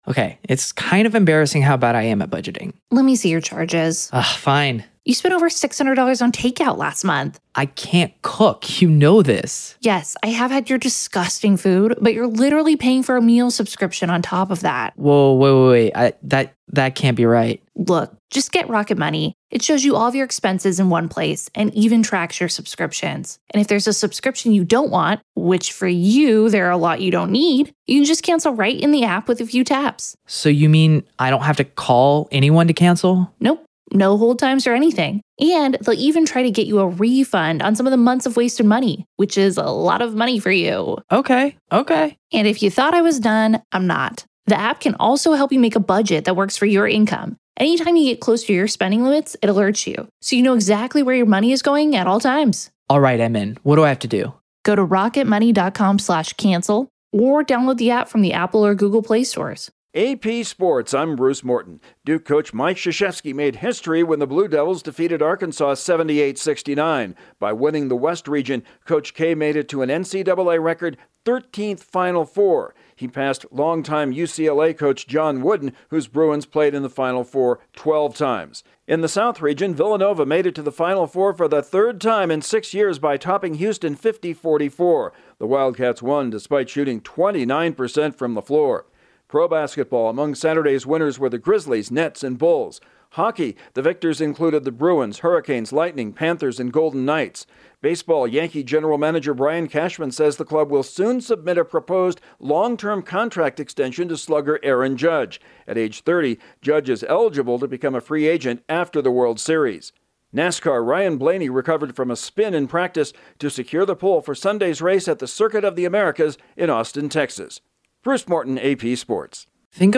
Duke and Villanova head to the Final Four, the Yankees promise a contract offer to their top slugger and Ryan Blaney will be a pole-sitter on Sunday. Correspondent